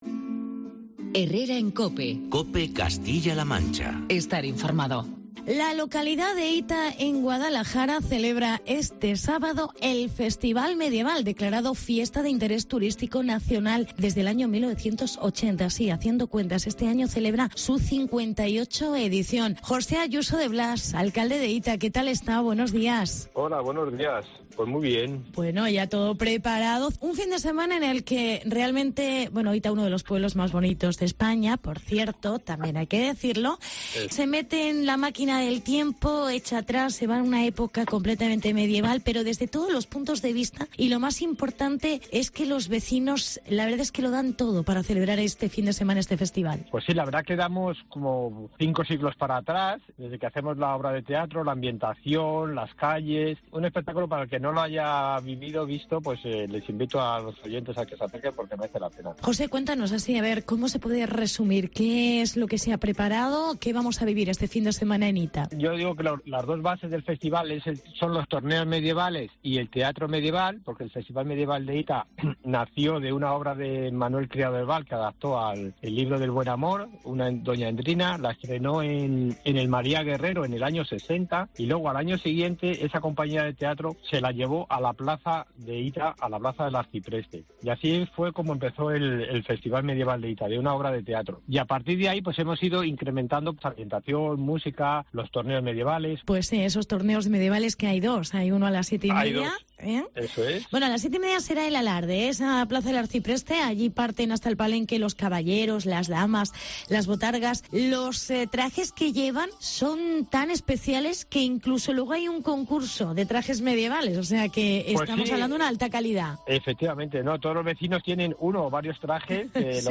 Entrevista con el alcalde de Hita: José Ayuso de Blas